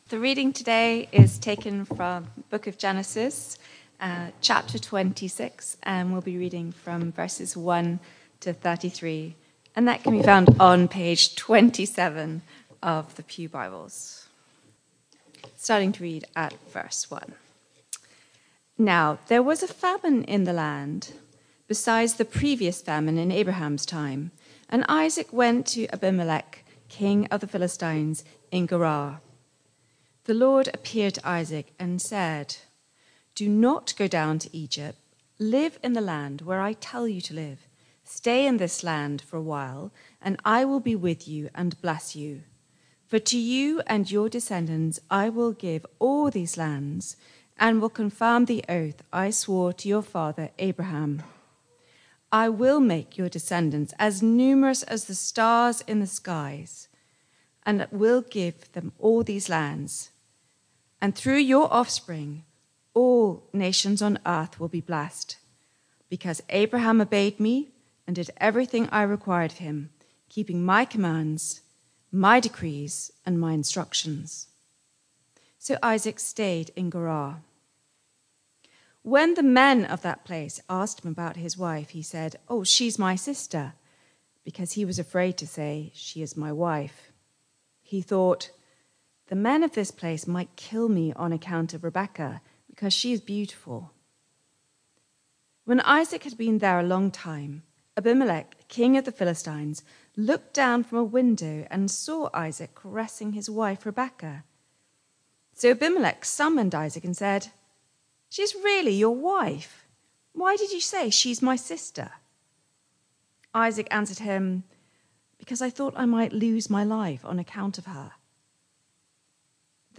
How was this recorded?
Barkham Morning Service